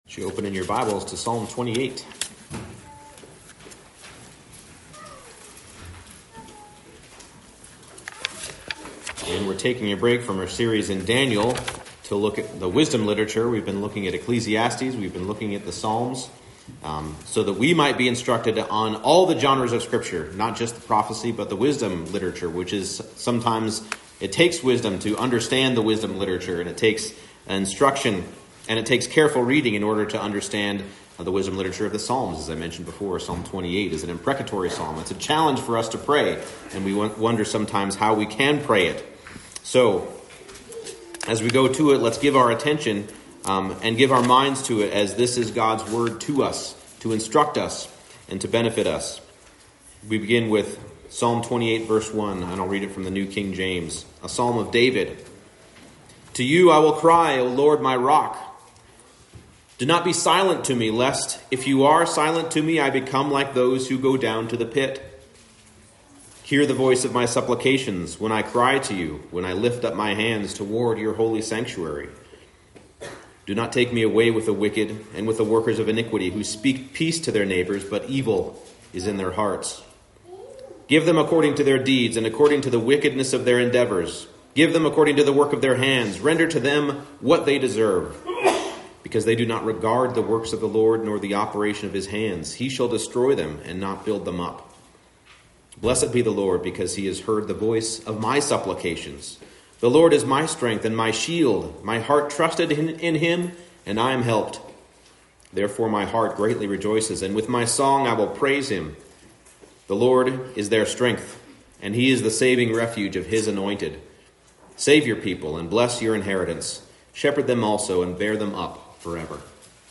Psalm 28 Service Type: Morning Service God’s people are shepherded and sustained to live holy lives by His covenant love.